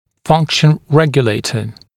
[‘fʌŋkʃ(ə)n ‘regjəleɪtə][‘фанкш(э)н ‘рэгйулэйтэ]регулятор функции (аппарат Френкеля)